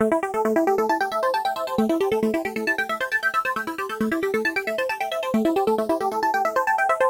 上升的凸缘FX循环
描述：一个上升的法兰西效果的循环，适合任何舞蹈类型，可以放慢速度来放松，我建议用Audacity波形编辑器来做。
标签： 135 bpm Trance Loops Fx Loops 1.20 MB wav Key : Unknown
声道立体声